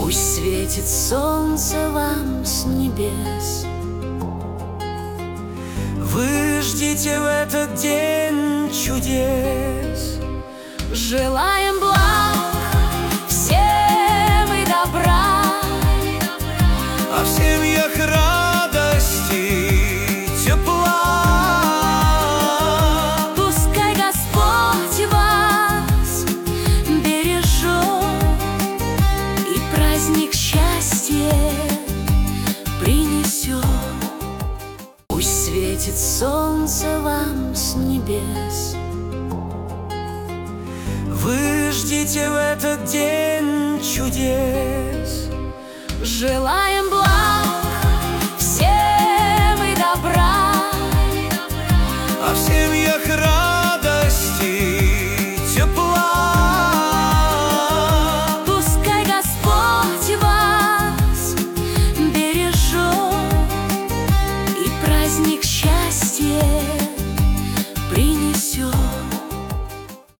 Качество: 320 kbps, stereo
Нейросеть Песни 2025